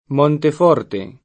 Monteforte [montef0rte] top.